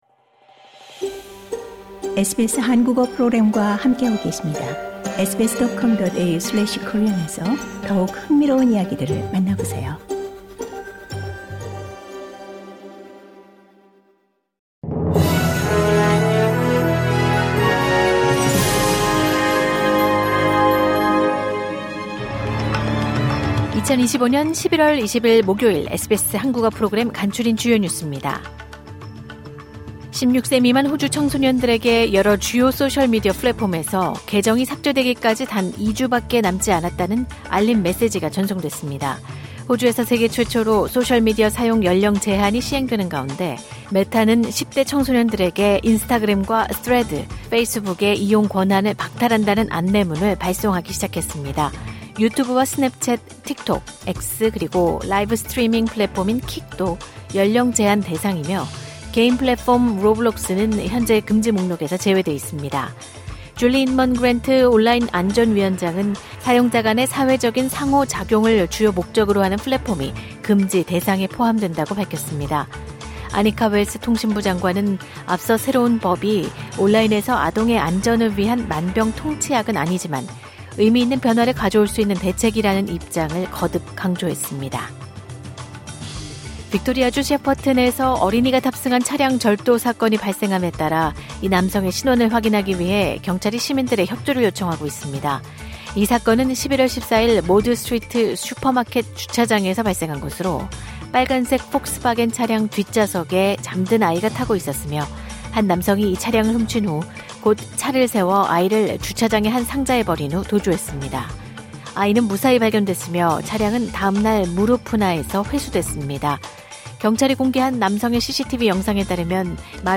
호주 뉴스 3분 브리핑: 2025년 11월 20일 목요일